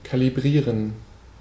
Ääntäminen
IPA: /kaliˈbʁiːʁən/ IPA: [kʰaliˈbʁiːɐ̯n]